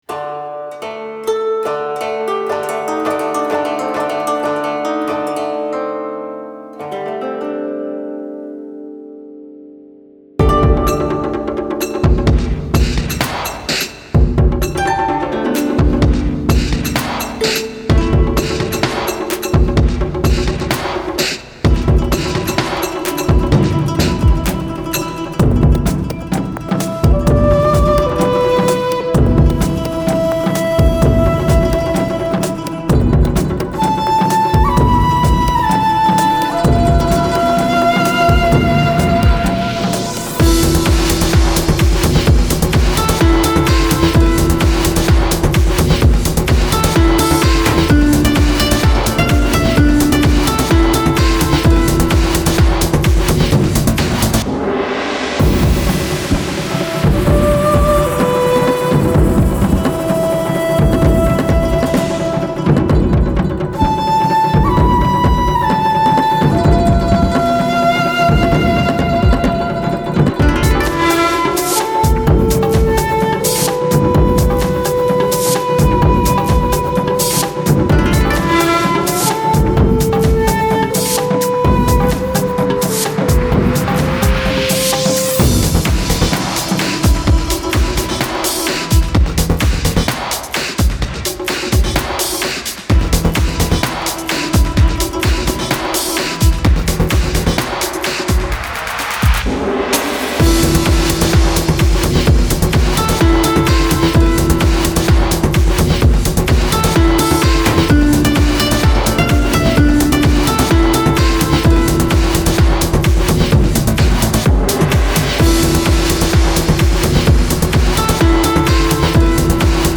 Audio: Japanese